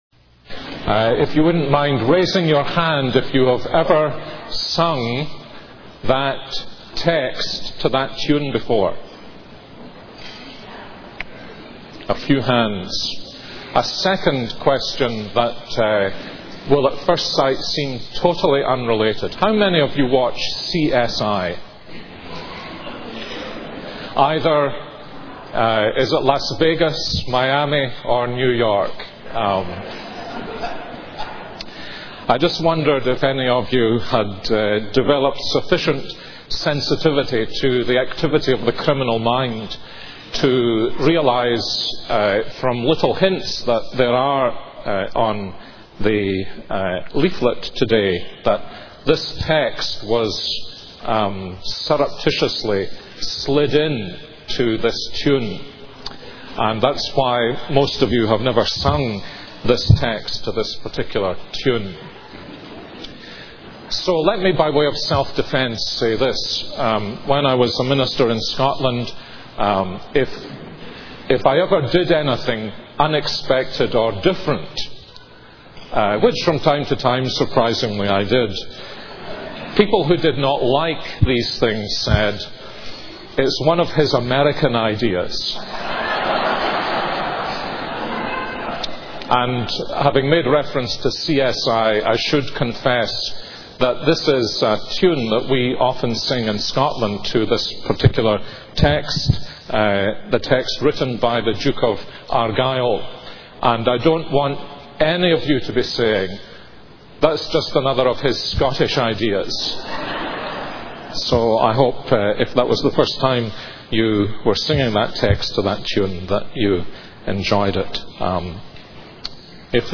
This is a sermon on Psalm 121.